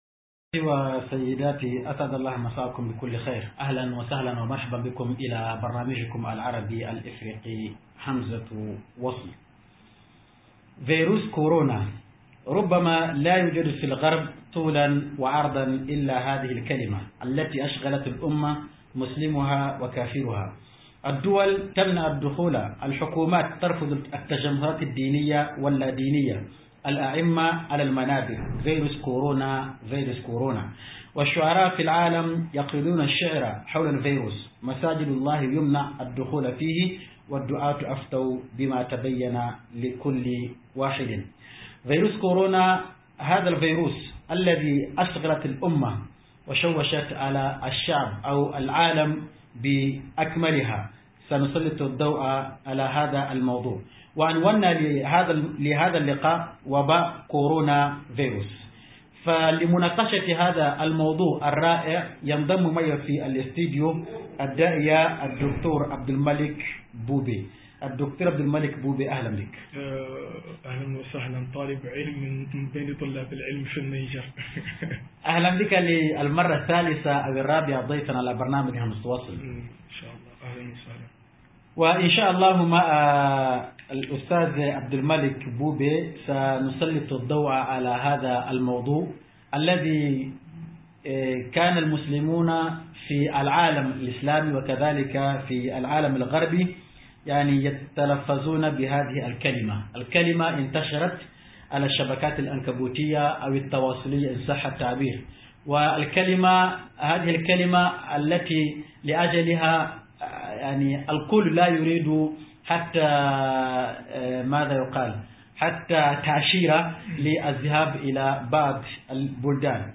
65-BNFERY ARABE CORONAVIRUS - MUHADARA